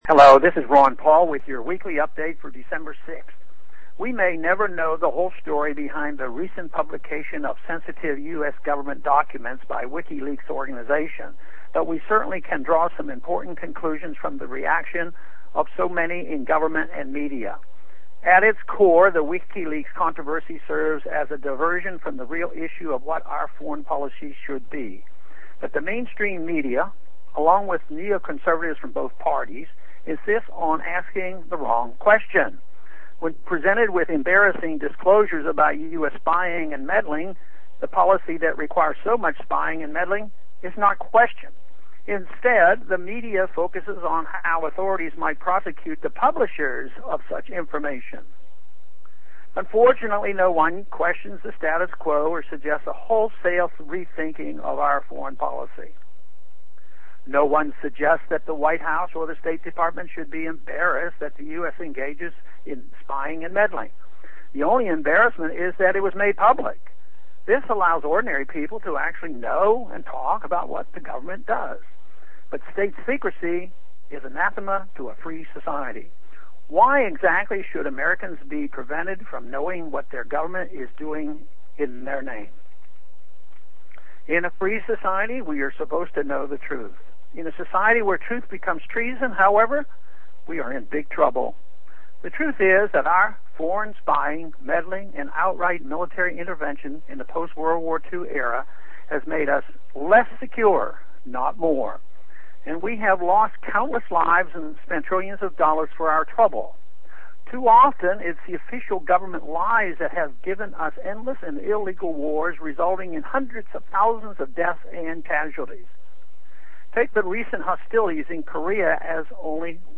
To listen to Rep. Ron Paul deliver this address, click